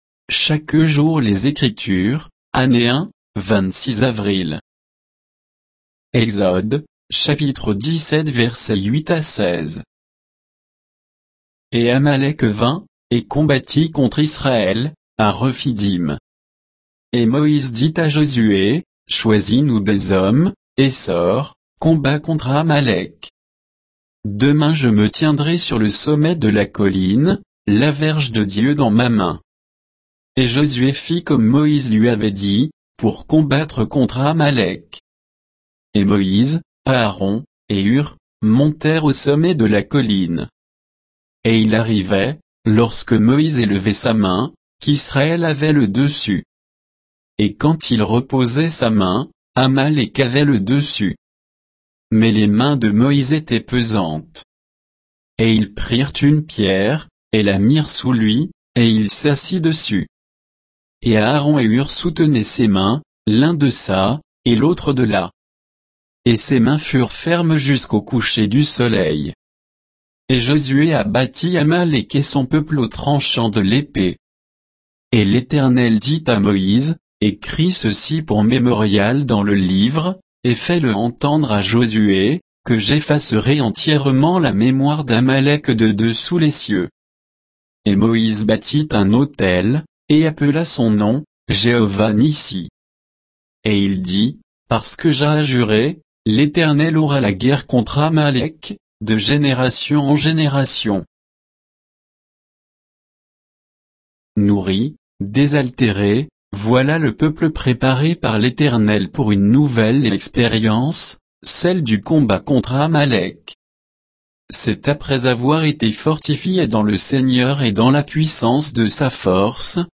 Méditation quoditienne de Chaque jour les Écritures sur Exode 17